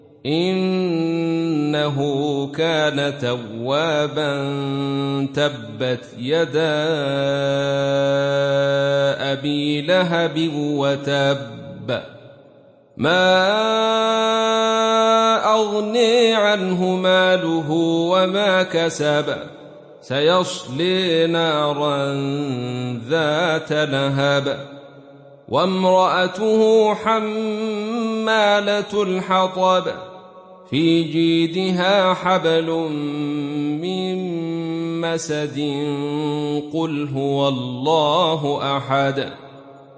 دانلود سوره المسد mp3 عبد الرشيد صوفي روایت خلف از حمزة, قرآن را دانلود کنید و گوش کن mp3 ، لینک مستقیم کامل